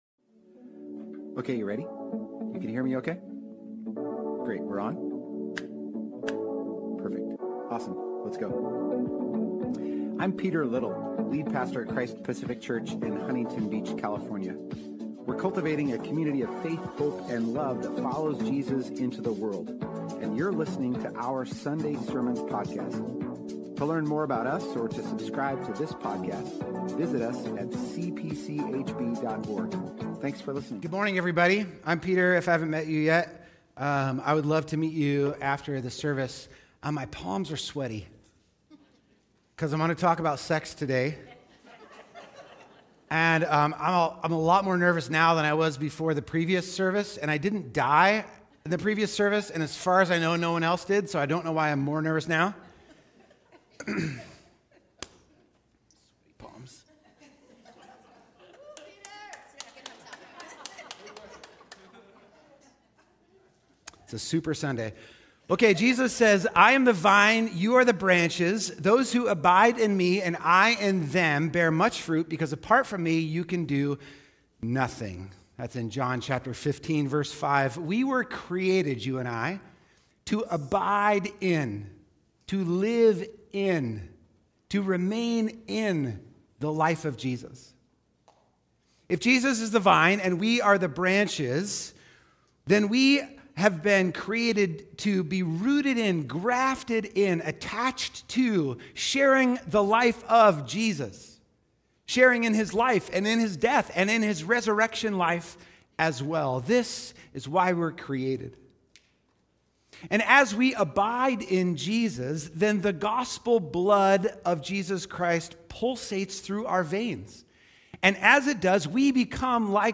"Sex" - Christ Pacific - A Local Huntington Beach Church